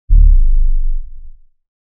Dark trap BRAAM — super heavy cinematic sub horn blast, distorted low-end roar, thick mid-bass body, short punchy transient, atmospheric tail, evil and modern sound design, no melody, royalty-free for music production
dark-trap-braam--super-vxz5ibio.wav